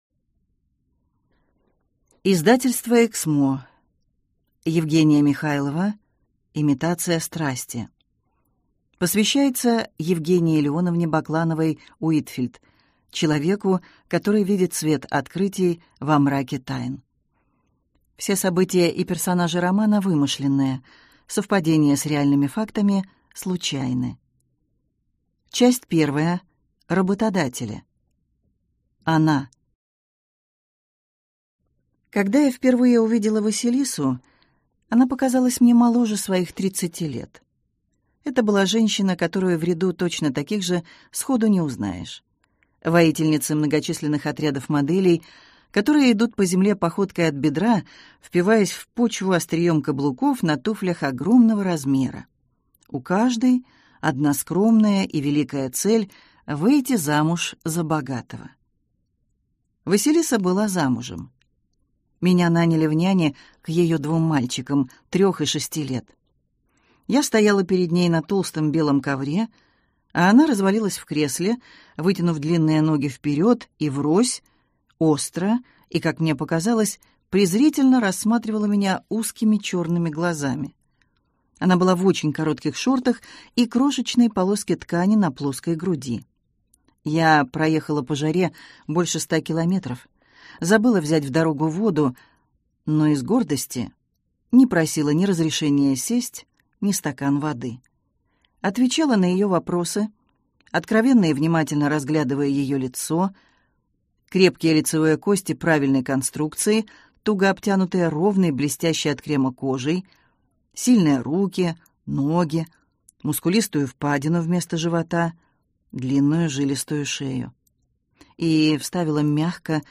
Аудиокнига Имитация страсти | Библиотека аудиокниг